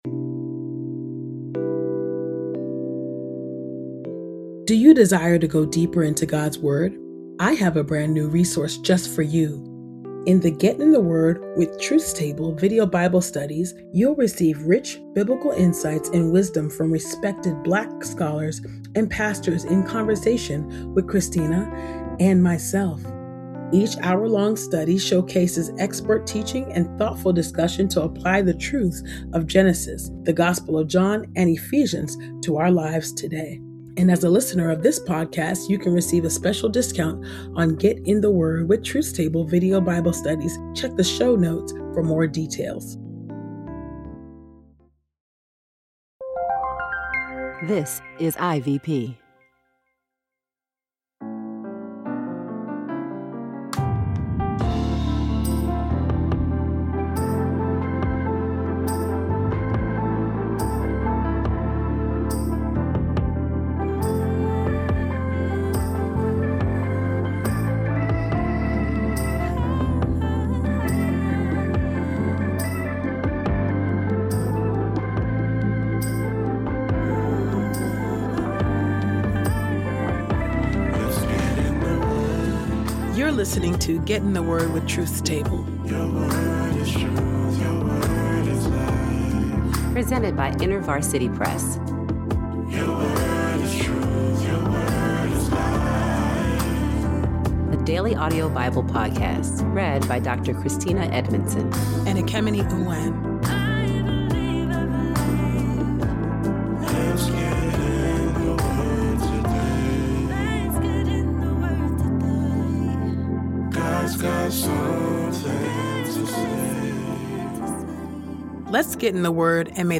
Sound engineering: Podastery Studios